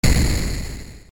爆発 短1
/ H｜バトル・武器・破壊 / H-10 ｜爆発音 / 1main